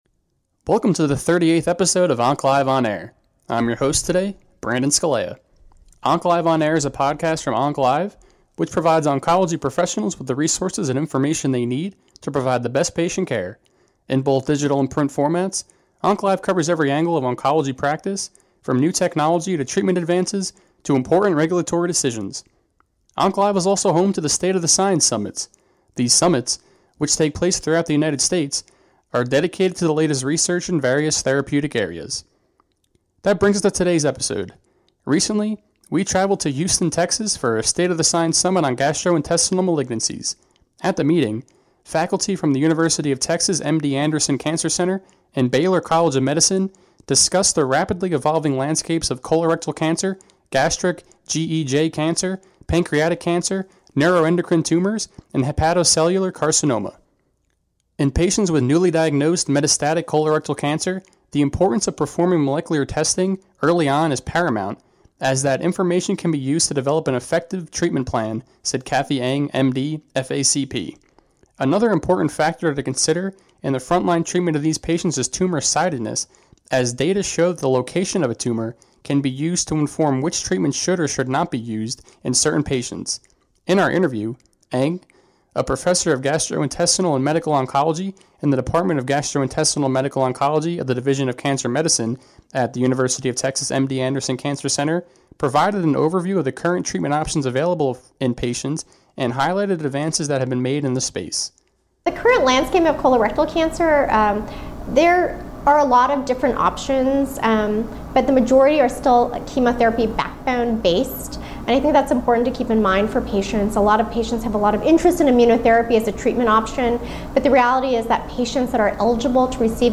Recently, we traveled to Houston, Texas, for a State of the Science Summit™ on Gastrointestinal Malignancies. At the meeting, faculty from the University of Texas MD Anderson Cancer Center and Baylor College of Medicine, discussed the rapidly evolving landscapes of colorectal cancer, gastric/GEJ cancer, pancreatic cancer, neuroendocrine tumors, and hepatocellular carcinoma.